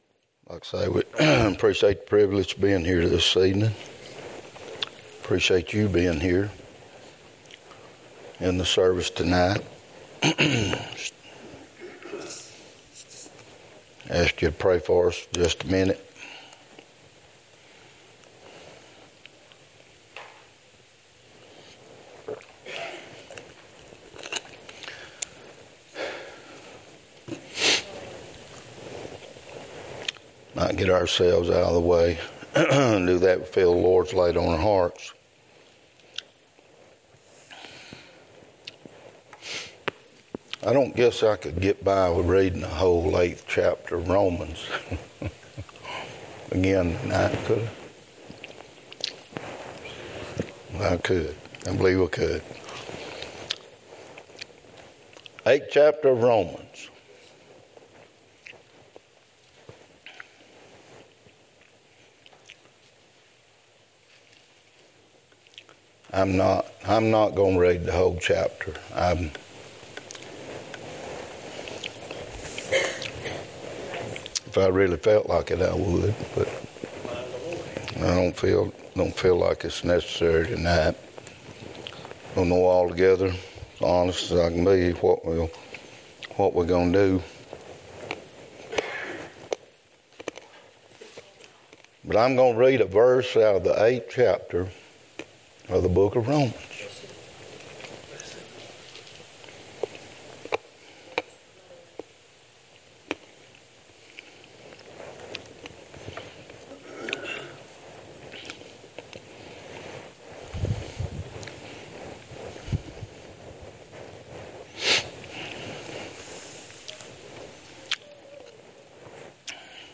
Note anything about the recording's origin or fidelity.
2024 Passage: Romans 8:37 Service Type: Wednesday night Topics